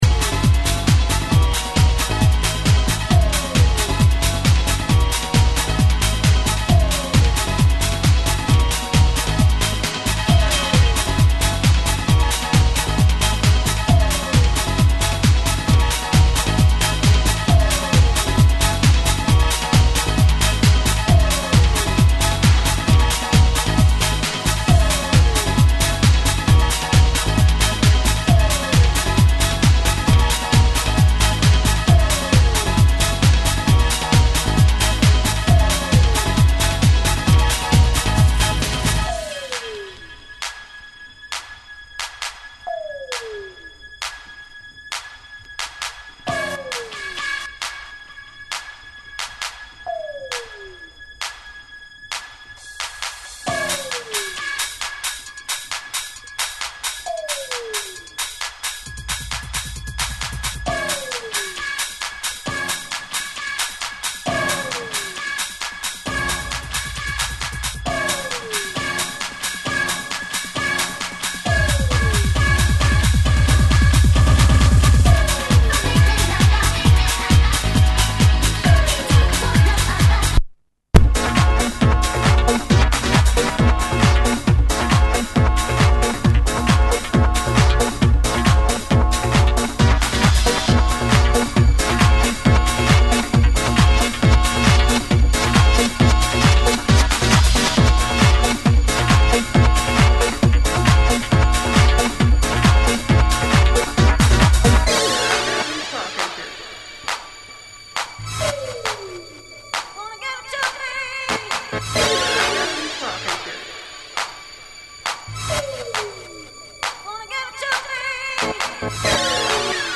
Old school house banger.